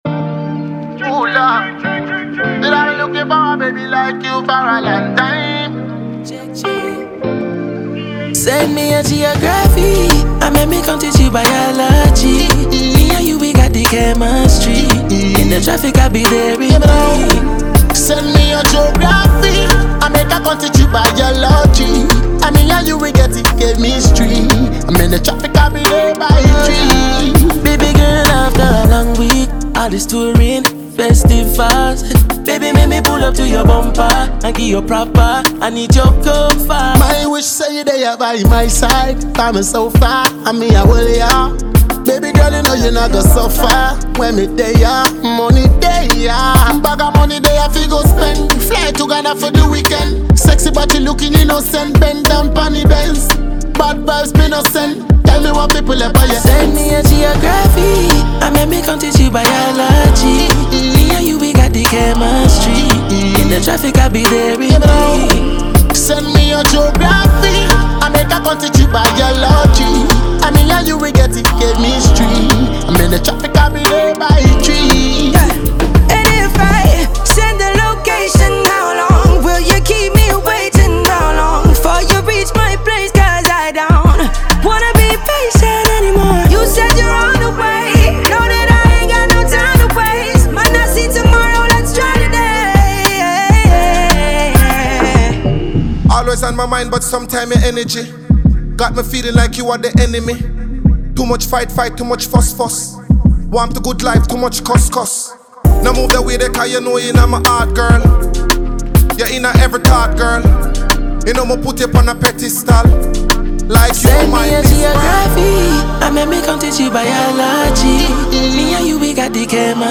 remix
This is a banger all day.